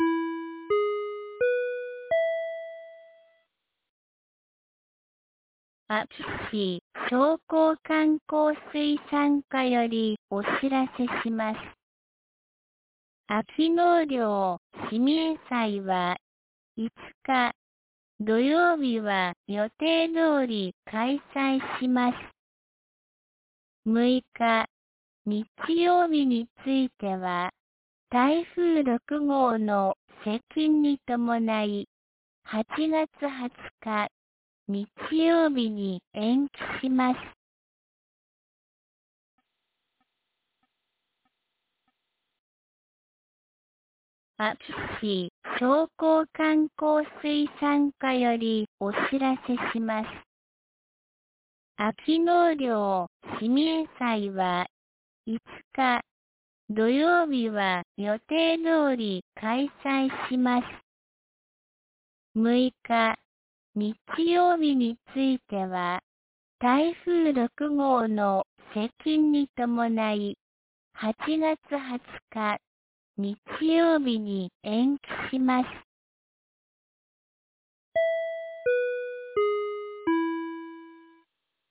2023年08月04日 12時31分に、安芸市より全地区へ放送がありました。